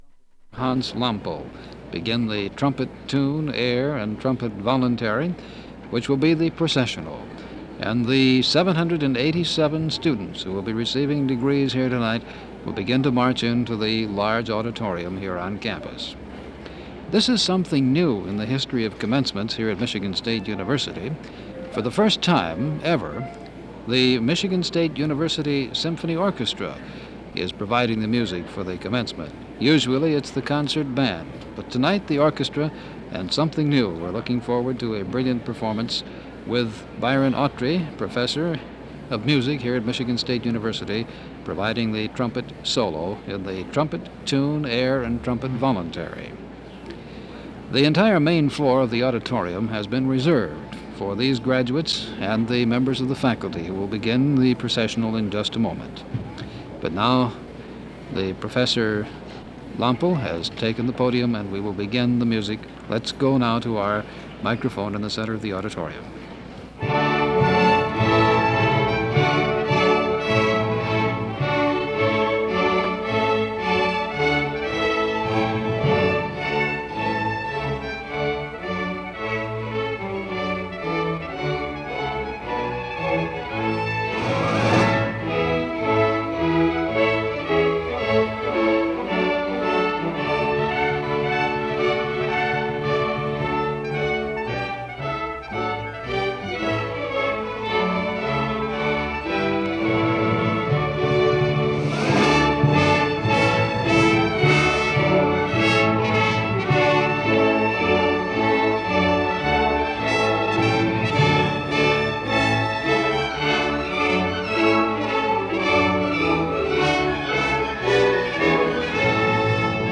Commencement Address, Winter 1963
The recording ends abruptly.
Open reel audio tape